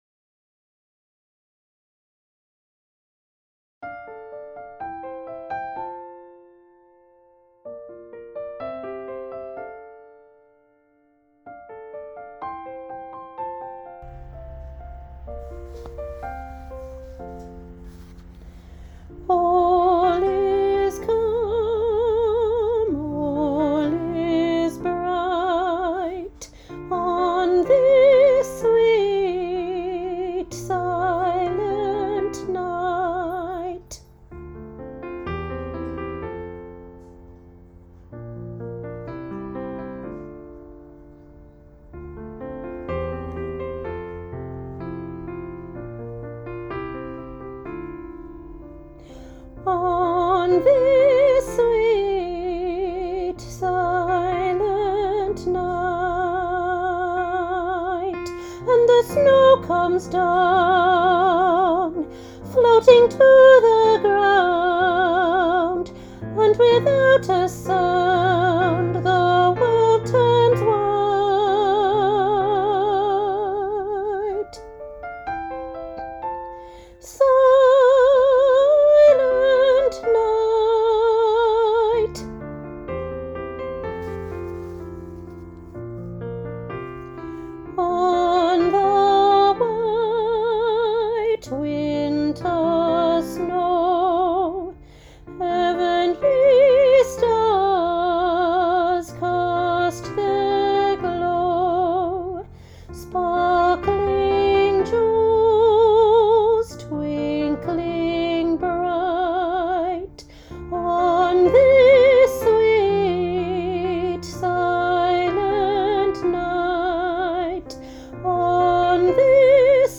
Junior Choir – Sweet Silent Night, Part 1
Junior-Choir-Sweet-Silent-Night-Part-1.mp3